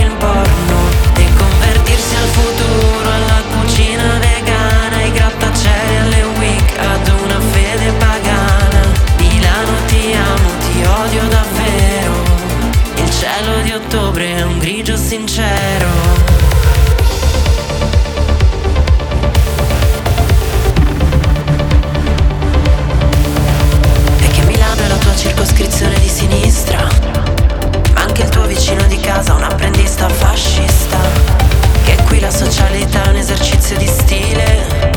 Жанр: Поп музыка